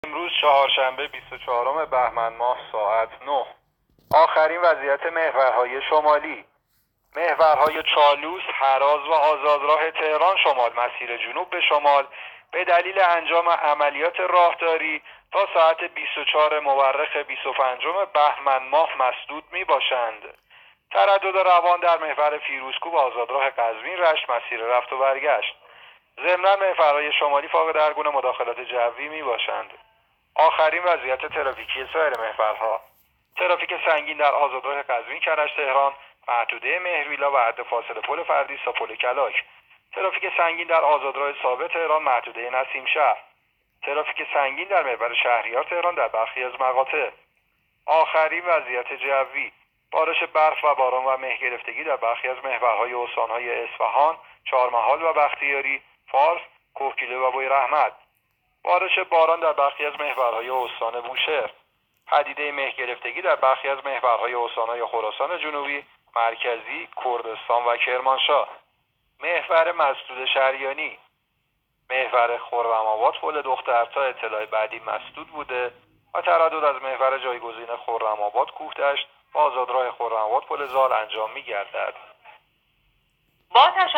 گزارش رادیو اینترنتی از آخرین وضعیت ترافیکی جاده‌ها ساعت ۹ بیست و چهارم بهمن؛